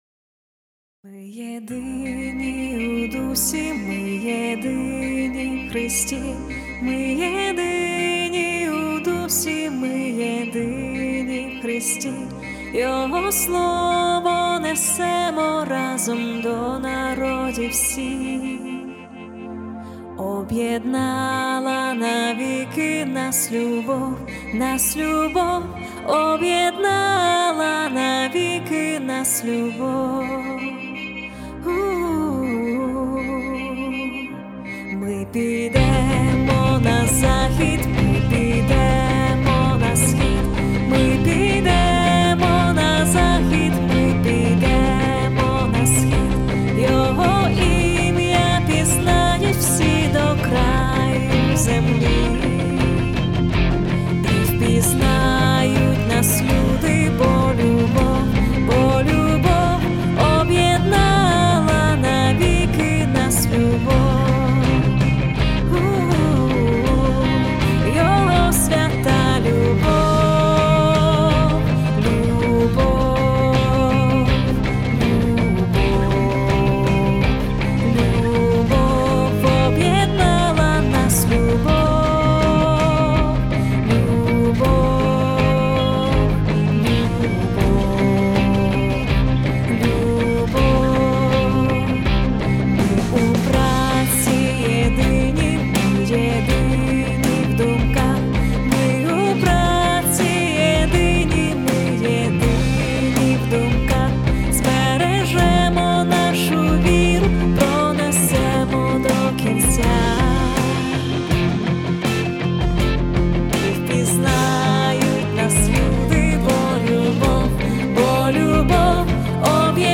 97 просмотров 145 прослушиваний 21 скачиваний BPM: 81